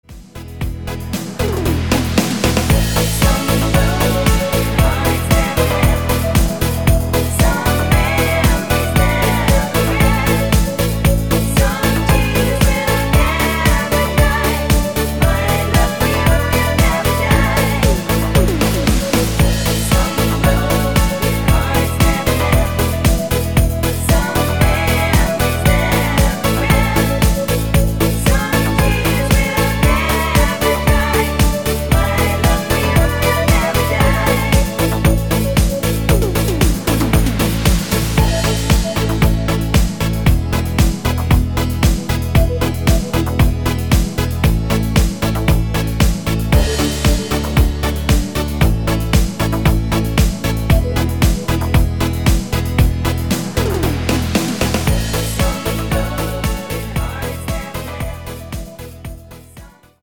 Der Country Klassiker